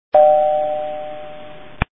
/32kbps) 16kbps (3.7кб) Описание: Звоночек ID 24936 Просмотрен 6474 раз Скачан 2100 раз Скопируй ссылку и скачай Fget-ом в течение 1-2 дней!